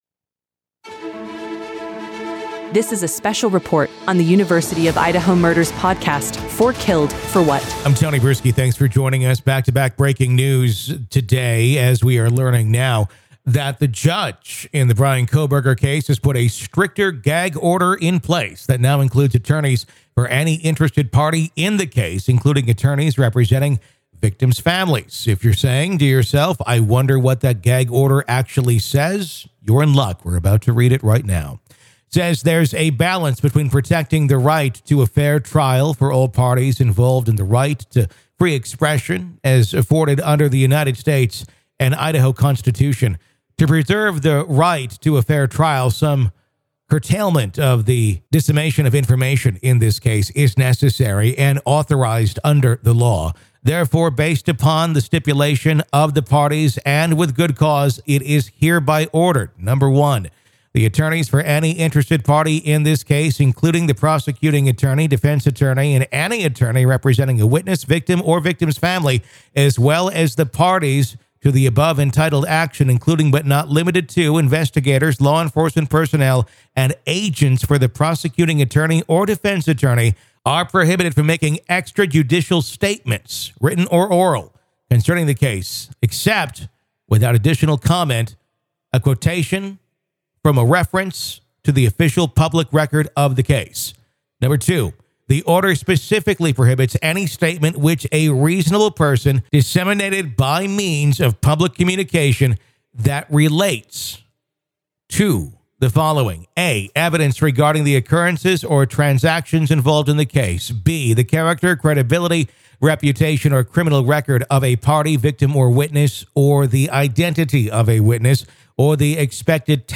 The judge in the Bryan Kohberger case has put a stricter gag order in place. Listen to hear the full reading of the gag order document.